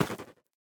Minecraft Version Minecraft Version 1.21.5 Latest Release | Latest Snapshot 1.21.5 / assets / minecraft / sounds / block / fungus / break3.ogg Compare With Compare With Latest Release | Latest Snapshot
break3.ogg